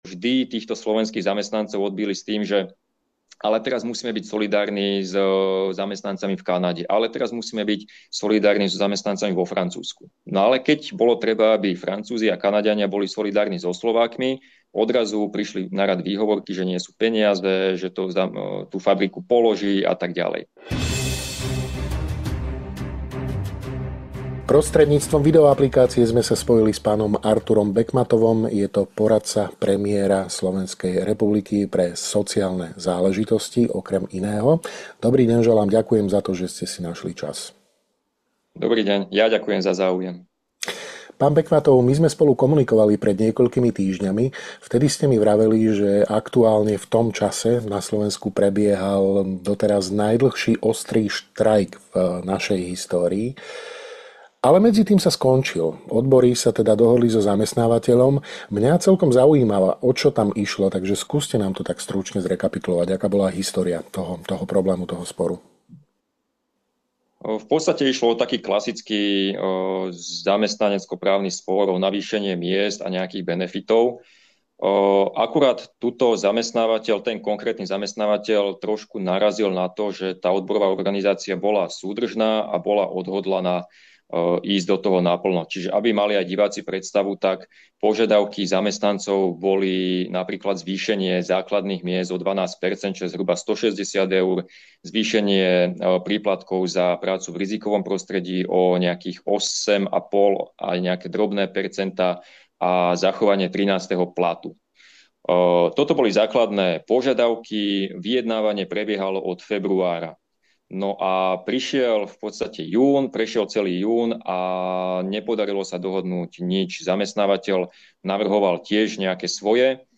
Rozprávali sme sa s poradcom premiéra SR